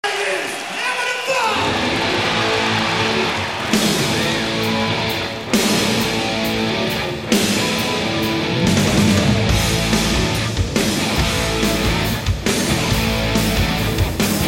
メインリフでの「（ジャージャチャッ）　どタン」というスネアとクラッシュの同時打ちからしてもう唯一無二！